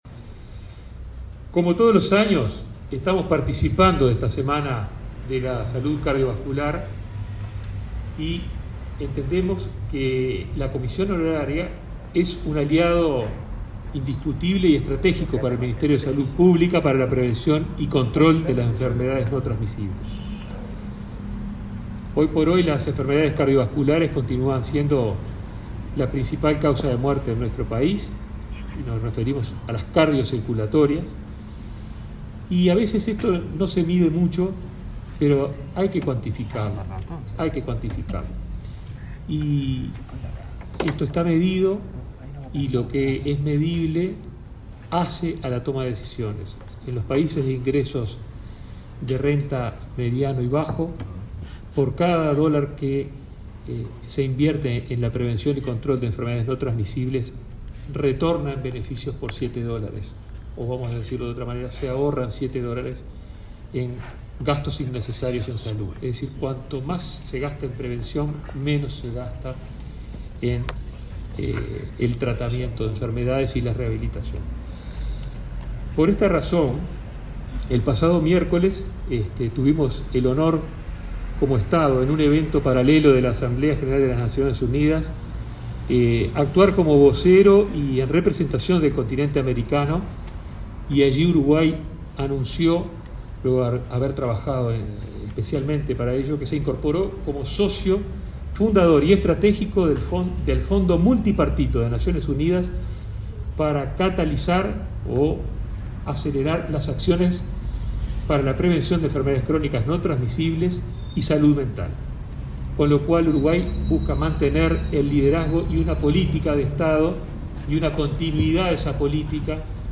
Palabras del ministro Daniel Salinas
Palabras del ministro Daniel Salinas 24/09/2021 Compartir Facebook X Copiar enlace WhatsApp LinkedIn Este viernes 24, el ministro de Salud Pública, Daniel Salinas, participó del lanzamiento de la 30.ª Semana del Corazón, que se realizó en la sede de esa secretaría de Estado.